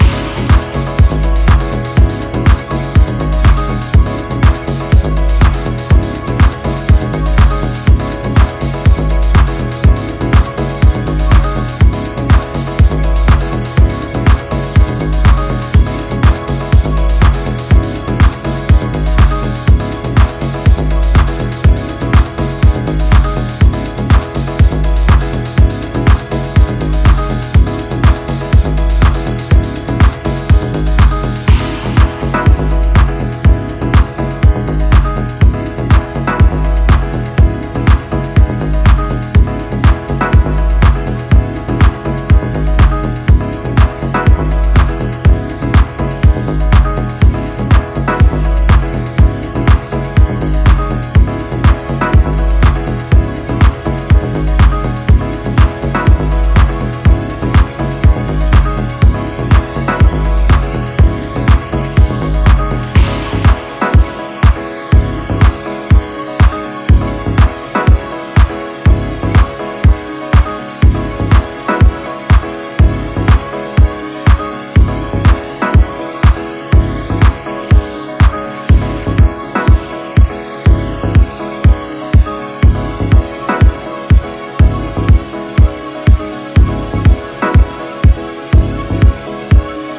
ジャンル(スタイル) DEEP HOUSE / HOUSE / TECHNO